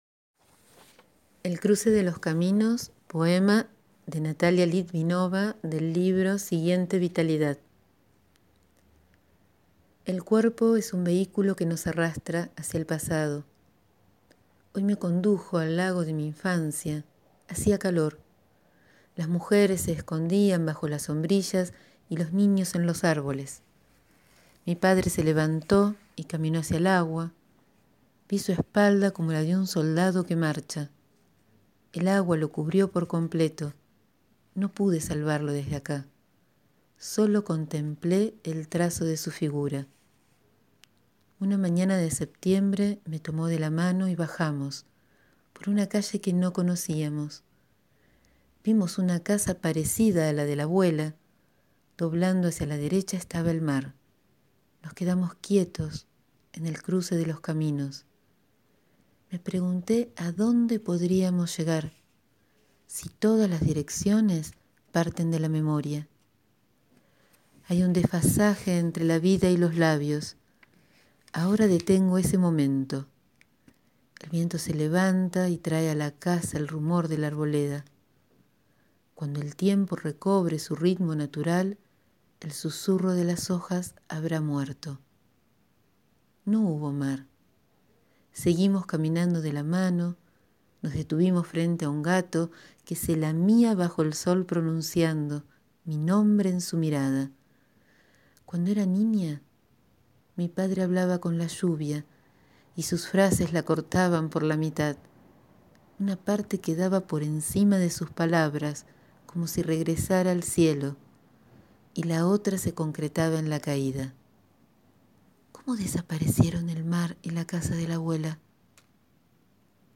Hoy leo el poema «El cruce de los caminos» de Natalia Litvinova.